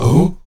Index of /90_sSampleCDs/Roland LCDP11 Africa VOL-1/VOX_Afro Chants/VOX_Ah Oos
VOX AHOO F08.wav